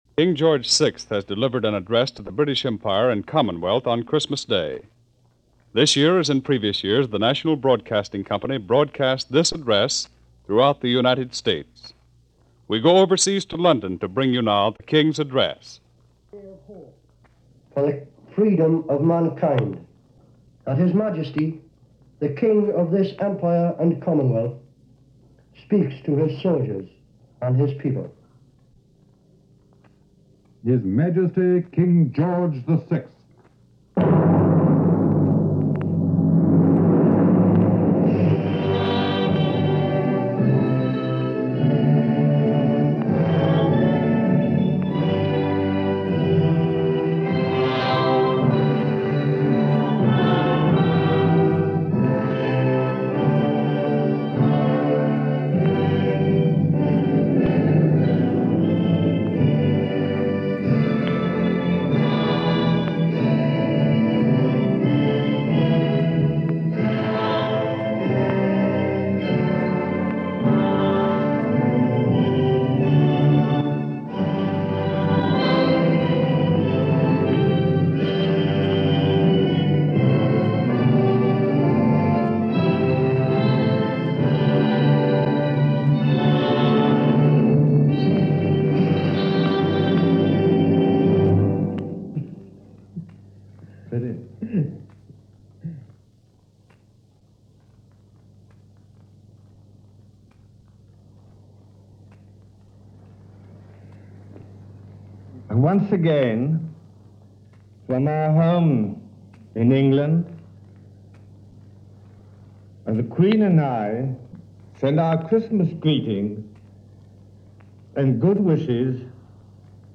King George VI – Christmas Day Message – Dec. 25, 1943 – BBC Home Service
King George VI, in an address to the people of the Commonwealth and the allies, on Christmas Day 1943.